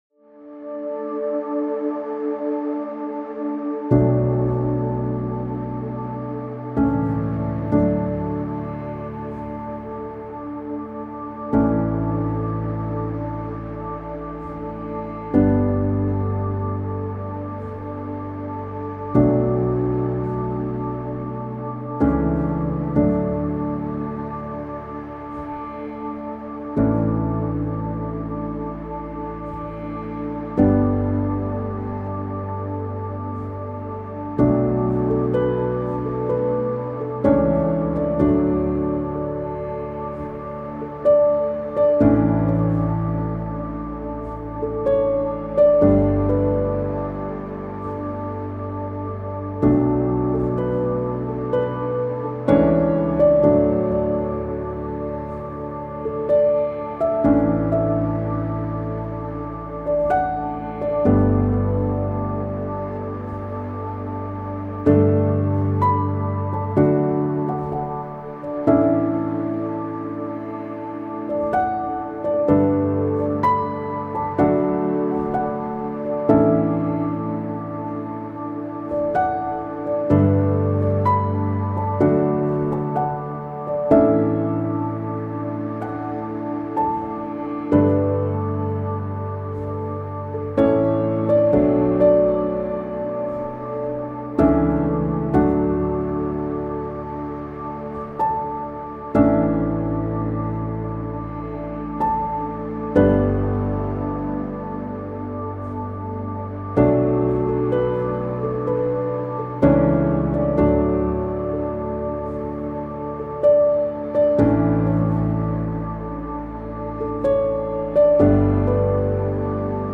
Instrumental: